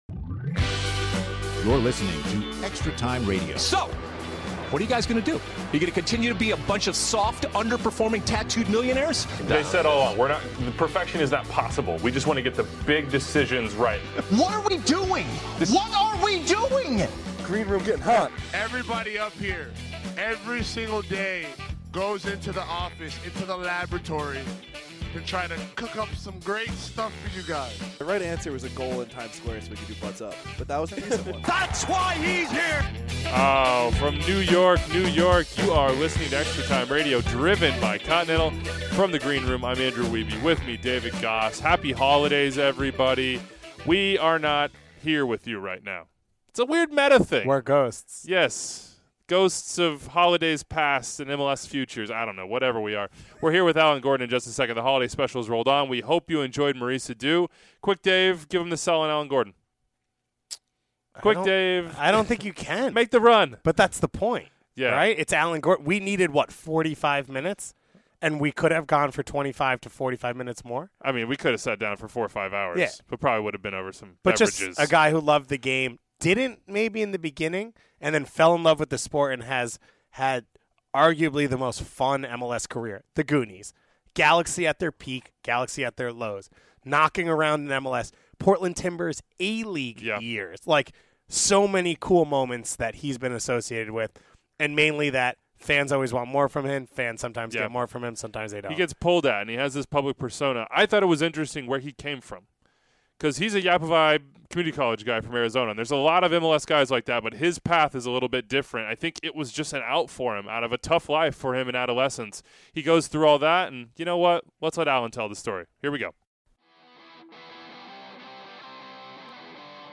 Our holiday specials roll on hour-long, in-depth interview with Alan Gordon. From scraping through Juco soccer to make it out, Portland Timbers A-League days, falling in love with soccer, finding his feet alongside David Beckham, and much more.